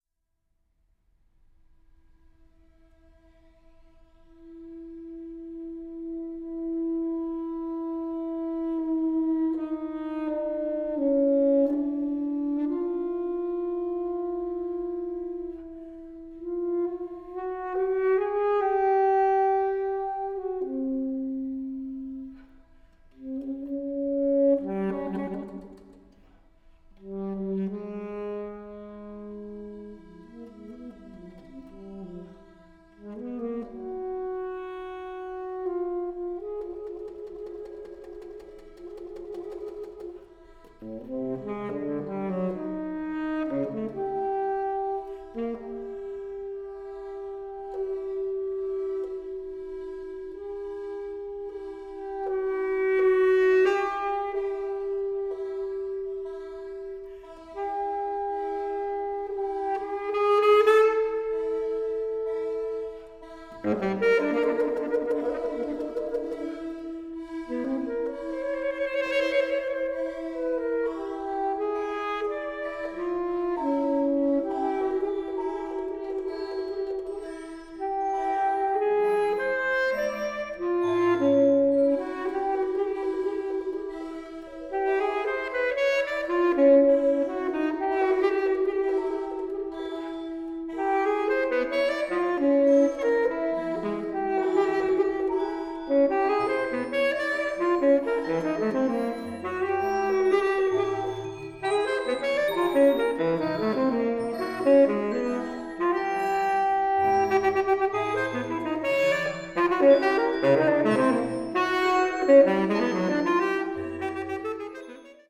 baritone saxophone
drums and vocals